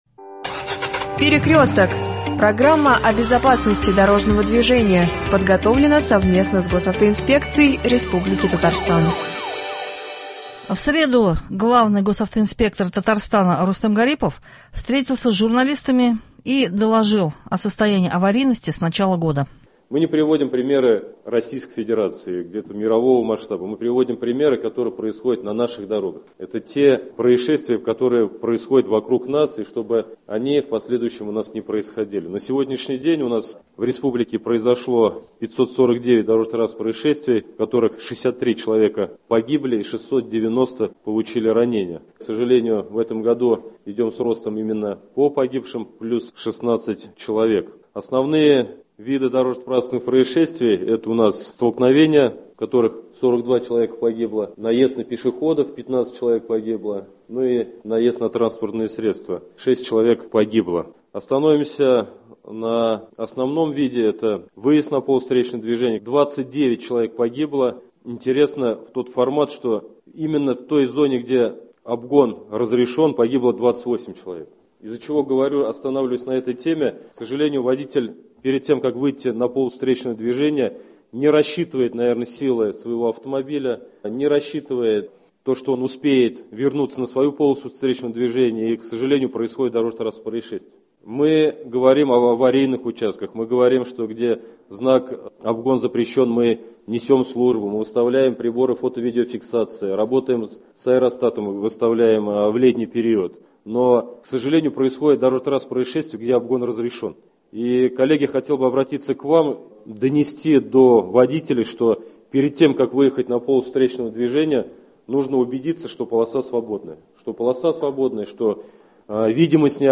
Пресс- конференция главного автоинспектора РТ Р. Гарипова.